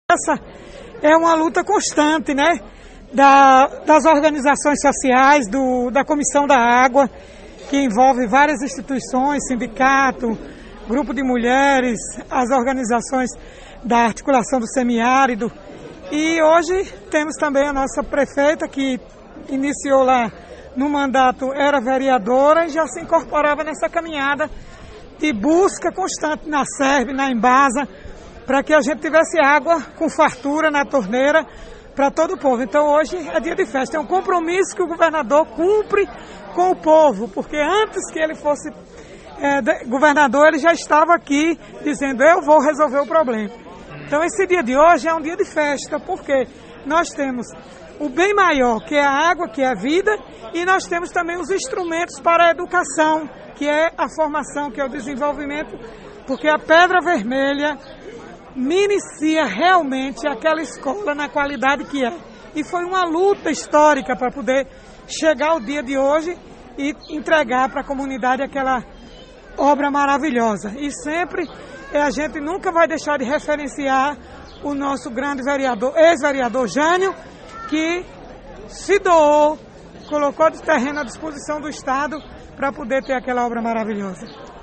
Em entrevista para o CN a parlamentar lembrar que é uma luta constante das organizações sociais, da comissão da água, a prefeita quando ainda era vereadora ,  – Ouça